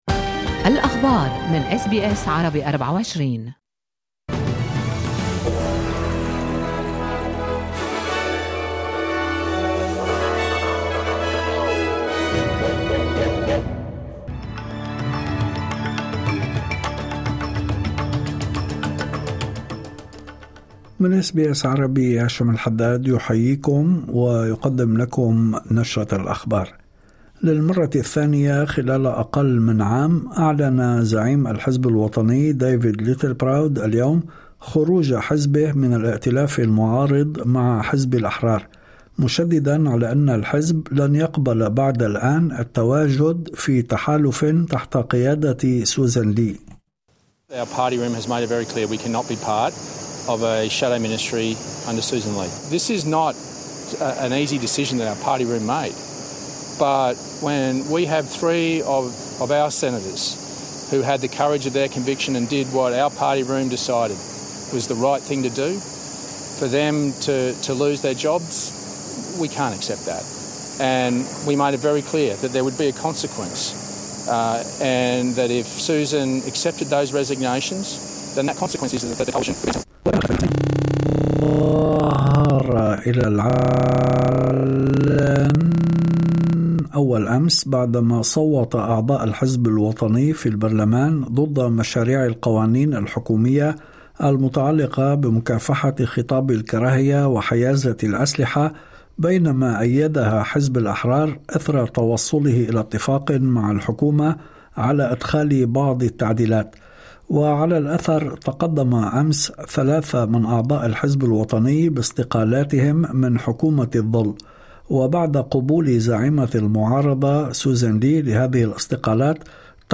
نشرة أخبار الظهيرة 22/1/2026
يمكنكم الاستماع الى النشرة الاخبارية كاملة بالضغط على التسجيل الصوتي أعلاه.